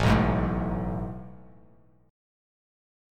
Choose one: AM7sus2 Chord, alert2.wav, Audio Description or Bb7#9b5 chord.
AM7sus2 Chord